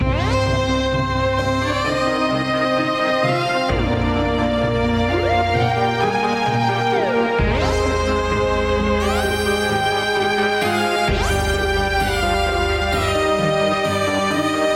心痛 弦乐合成器和电吉他
描述：这是一个Rnb类型的循环，混合了弦乐合成器、定音鼓和电吉他。
Tag: 130 bpm RnB Loops Groove Loops 2.48 MB wav Key : Unknown